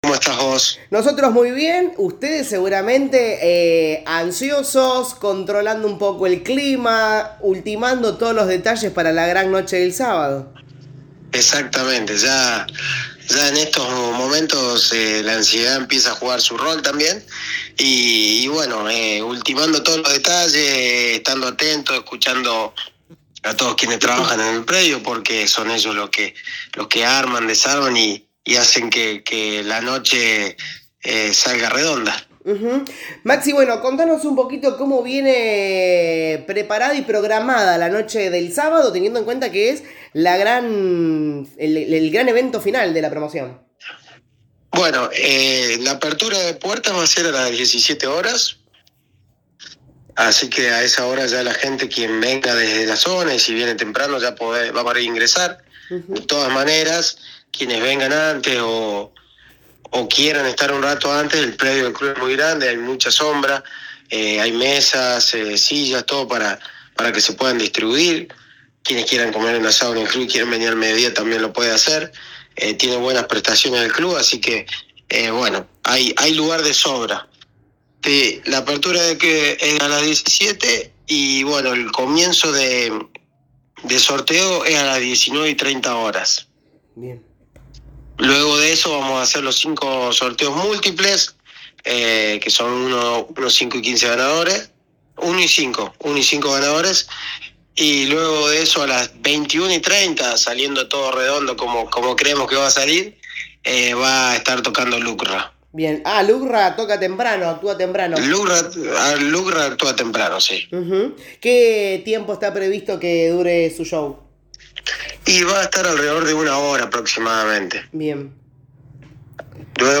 En diálogo con LA RADIO 102.9